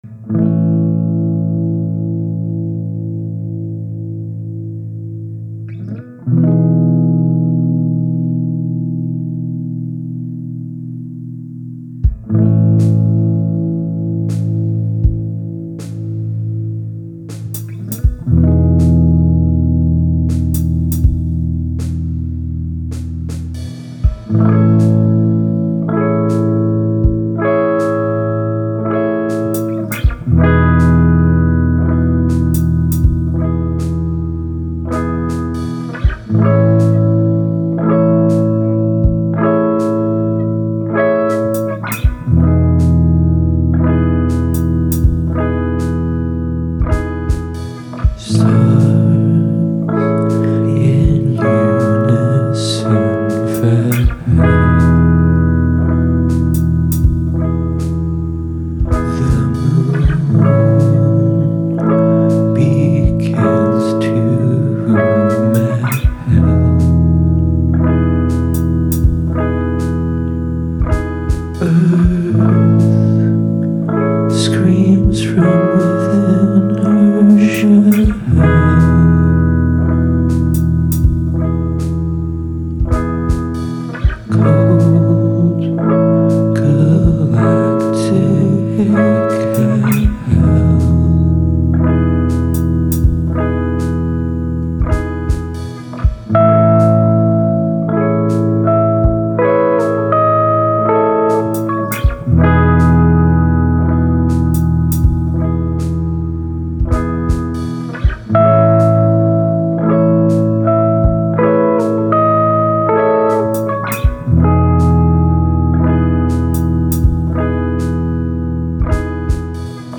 Indie
Solo artist making music in my bedroom.
Might also get a lil rowdy.